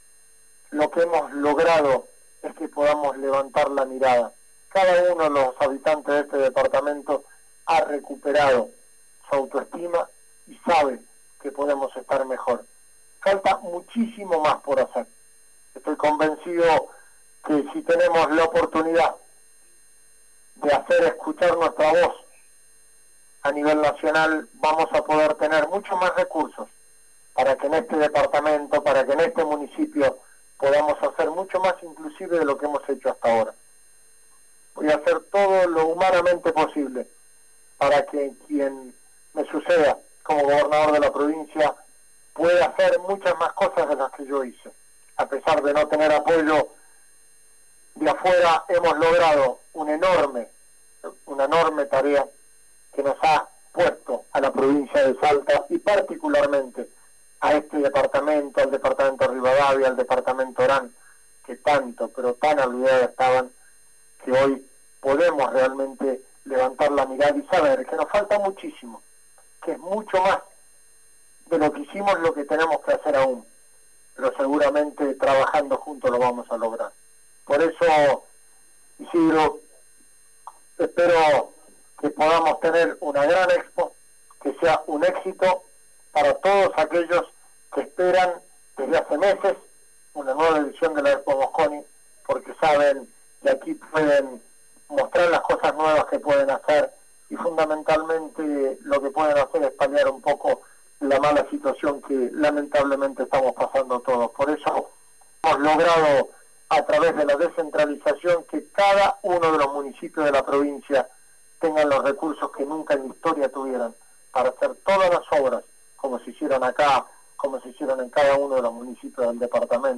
El gobernador de Salta, Juan Manuel Urtubey encabezó la inauguración de la Expo Mosconi 2019, y en su discurso destacó la posibilidad de que haya actividades económicas en la región.
rsm-discurso-urtubey.wav